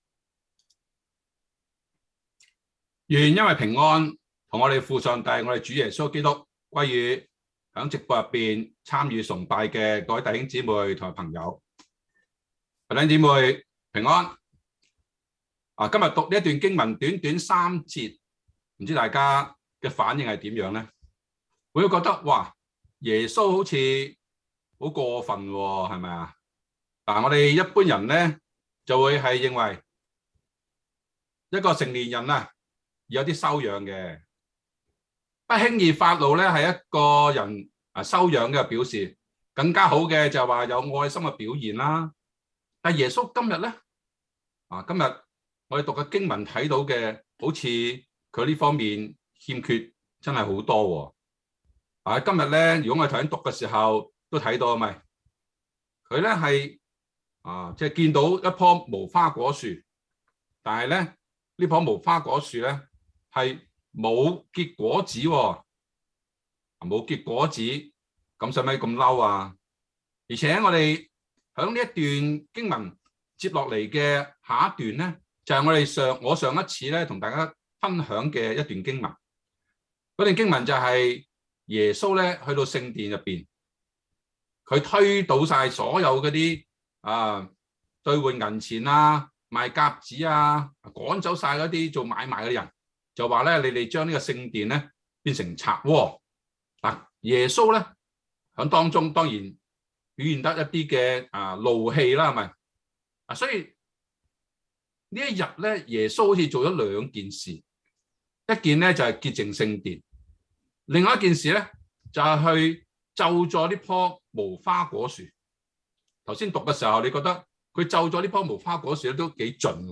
sermon0220Canto.mp3